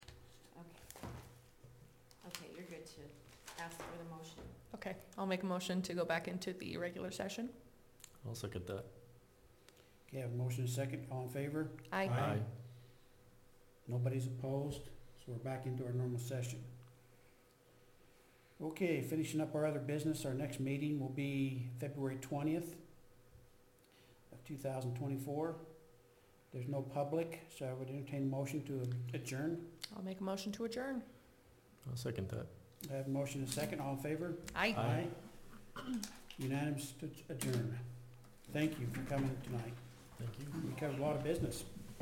Clarkston Town Council Meeting
Meeting